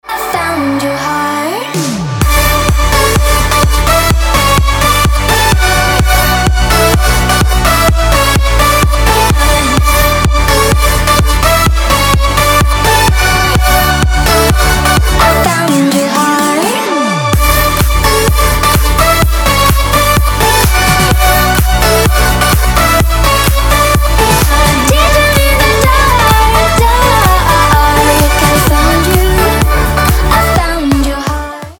club
progressive house